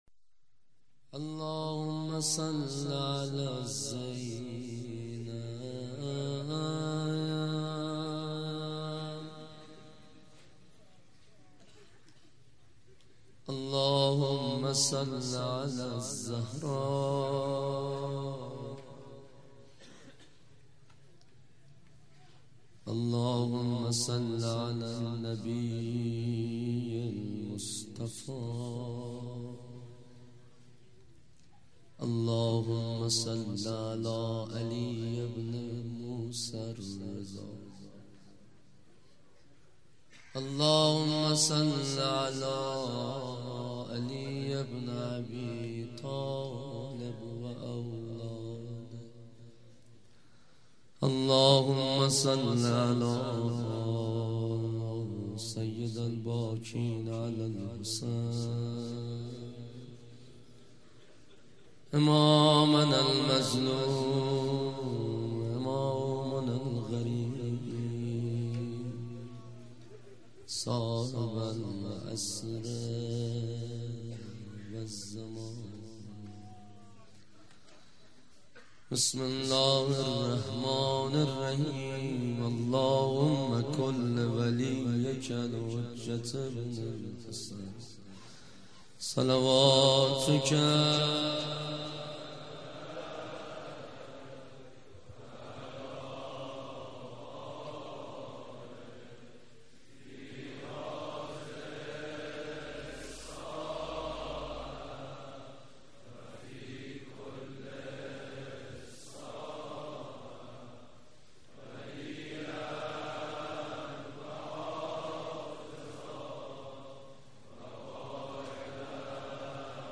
روضه شب هشتم